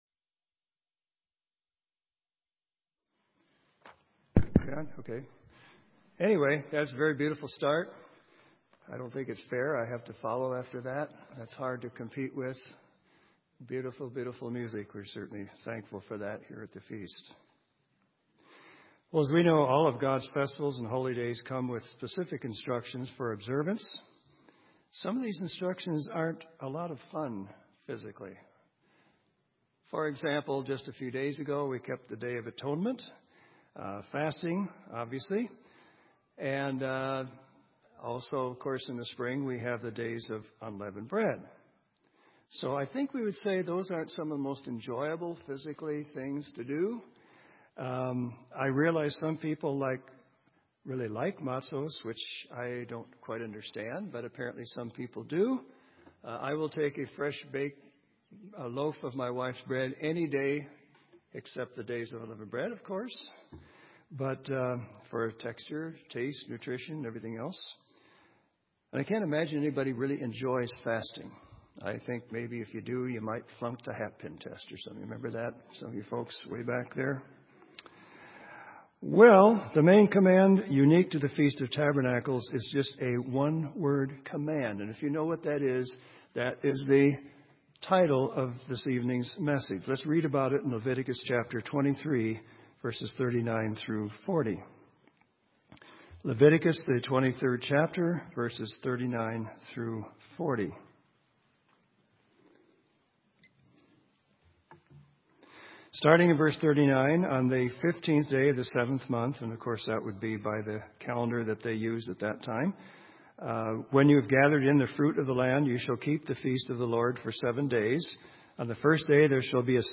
This sermon was given at the Bend, Oregon 2016 Feast site.